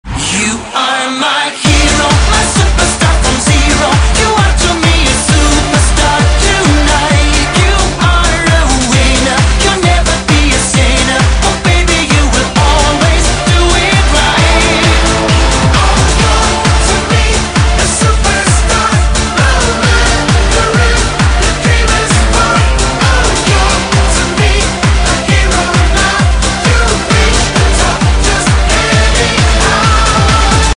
реалтоны pop